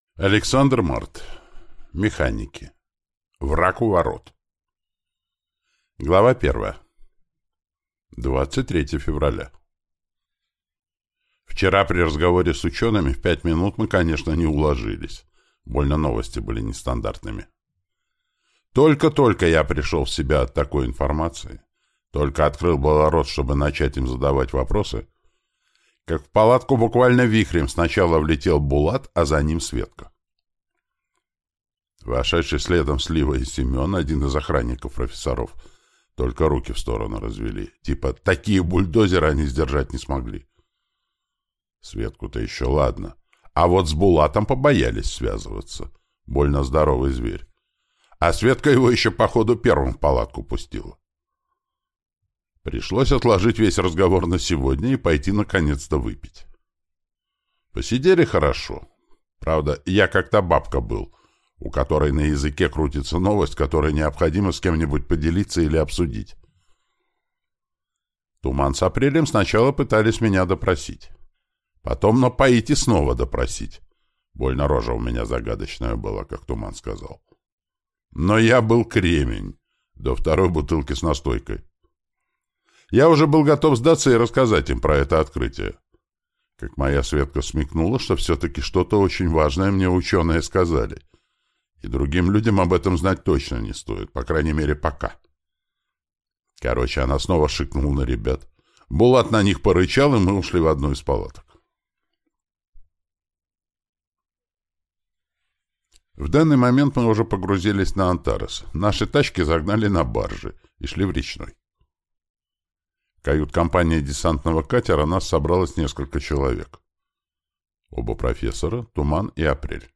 Аудиокнига Механики. Враг у ворот | Библиотека аудиокниг